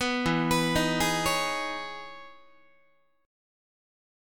Listen to EM13 strummed